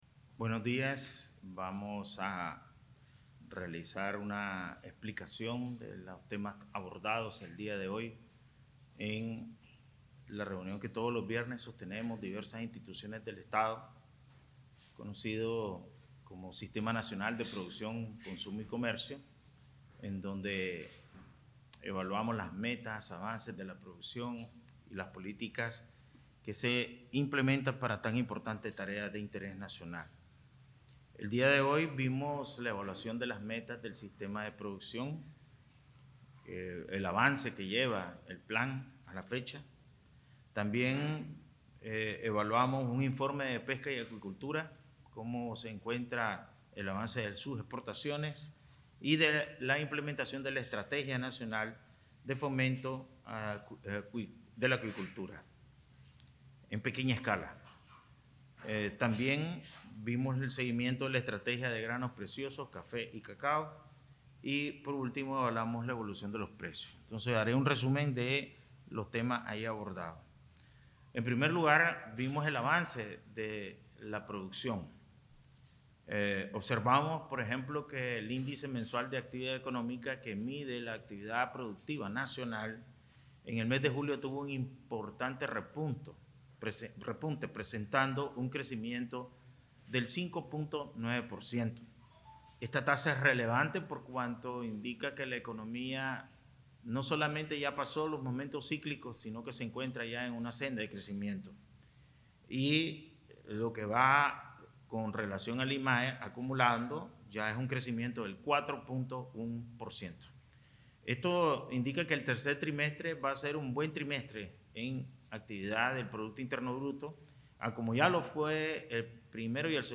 El Presidente del Banco Central de Nicaragua (BCN), Ovidio Reyes R., brindó el 29 de septiembre de 2023, un informe sobre los avances de la producción nacional, al término de la reunión semanal que realiza el Sistema Nacional de Producción, Consumo y Comercio (SNPCC), con el objetivo de evaluar las metas, avances y políticas que se implementan para tan importante tarea de interés nacional.